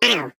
Sfx_tool_spypenguin_vo_hit_wall_05.ogg